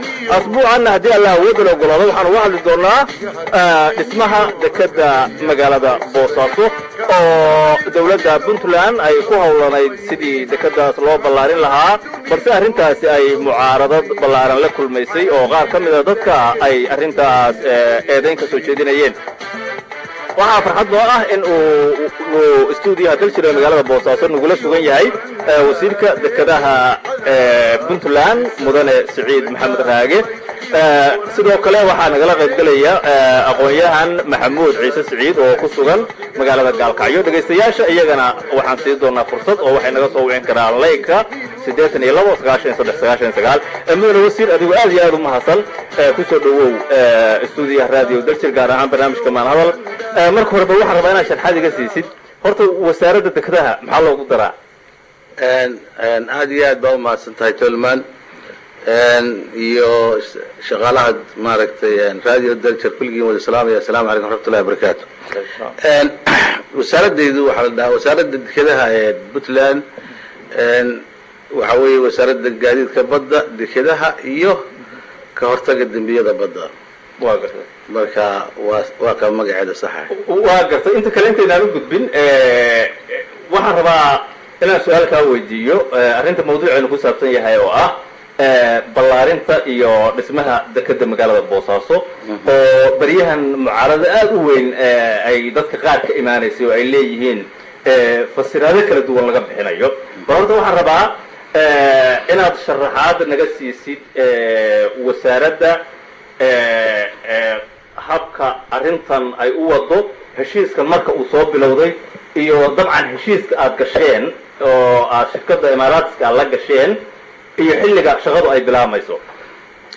Barnaamijka ayaa waxaa ka qaybgalay oo dhagaystayaasha su’aalahooda uga jawaabayey Wasiirka Dekedaha Puntland Siciid Maxamed Raage.